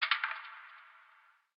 ambienturban_12.ogg